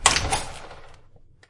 Old HOUS E气氛的声音 " 00110 drzwi saloon
描述：通过一个沙龙的门，来自Rode NT4和DAT录音机的单声道信号
标签： 门轿车 关闭 门轿车